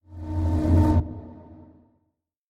Sound / Minecraft / mob / zombie / unfect.ogg